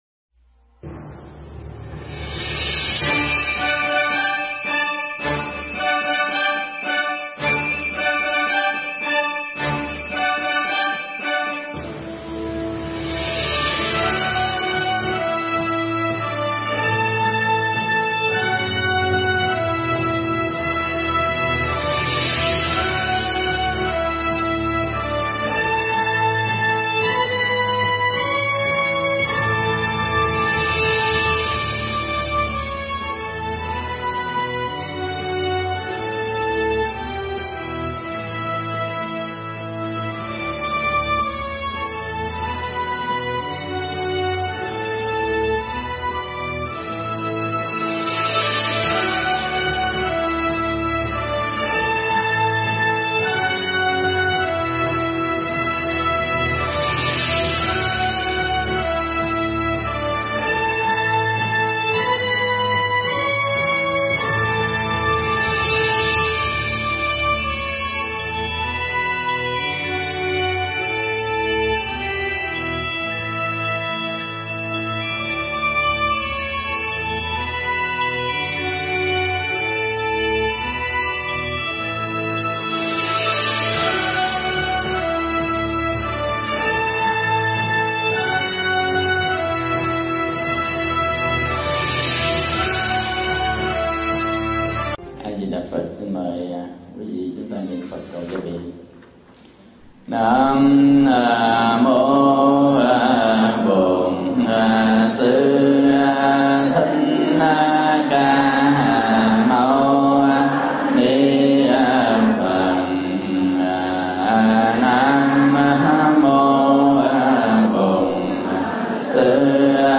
Mp3 Thuyết Pháp Bốn pháp chọn lựa để tu
thuyết giảng giảng tại Chùa Cổ Lâm, SEATTLE WA, USA (Nước Mỹ)